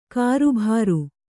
♪ kāṛubhāru